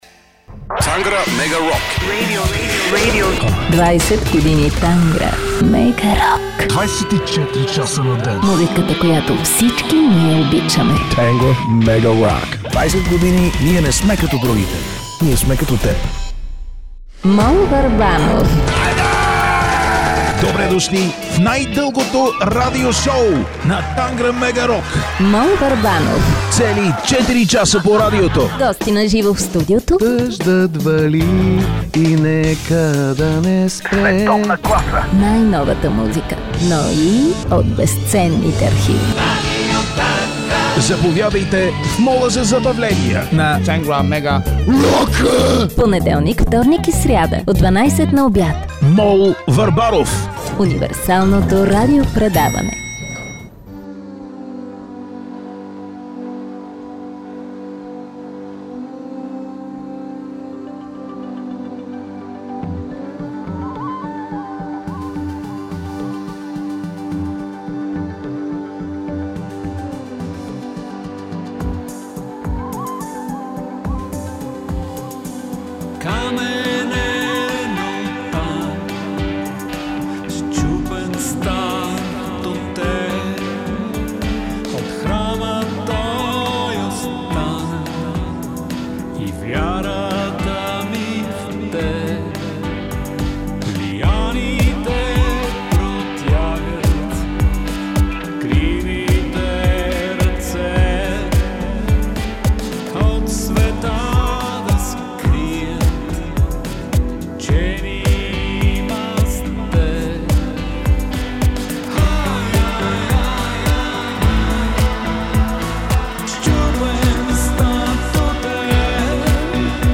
едно интервю